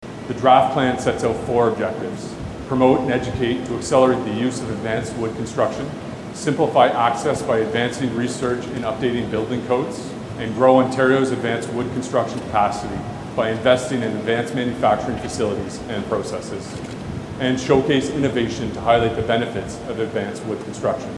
The announcement was made Tuesday morning in St. Thomas, with Paul Calandra, Minister of Municipal Affairs and Housing and Rob Flack, MPP for Elgin-Middlesex-London on hand.